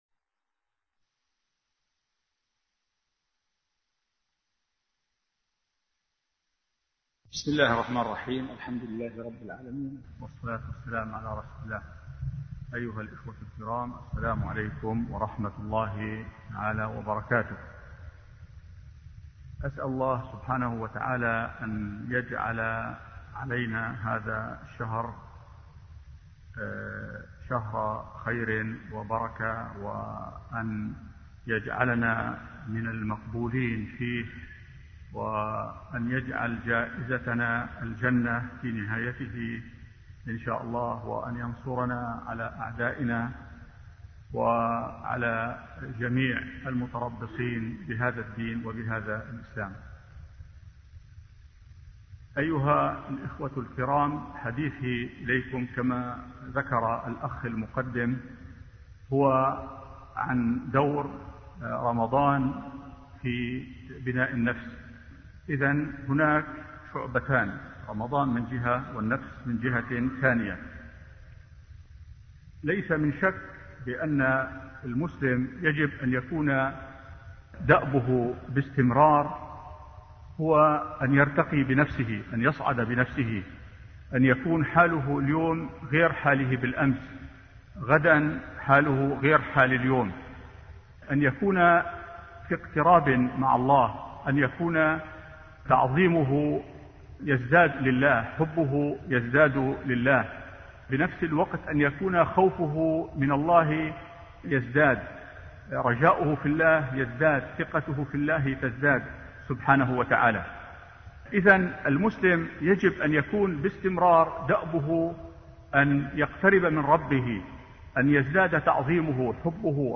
الجزء الأول من محاضرة دور رمضان في بناء النفس